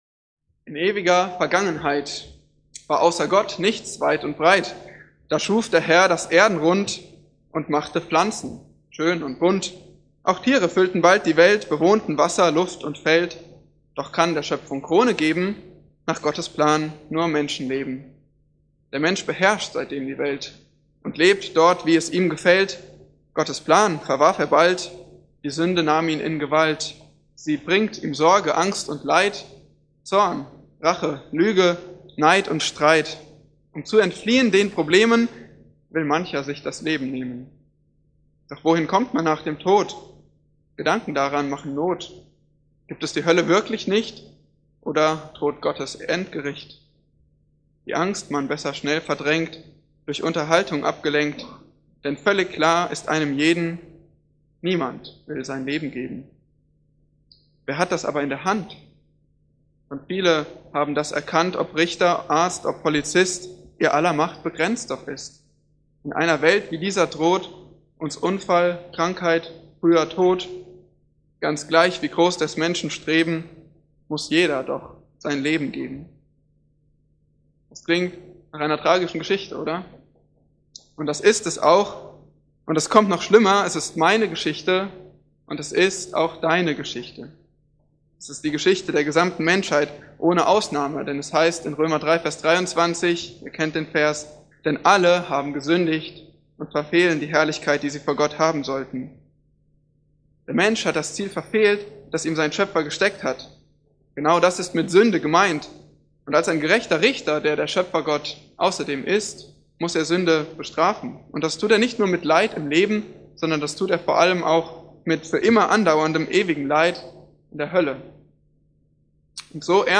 Eine predigt aus der serie "Biblische Heiligung."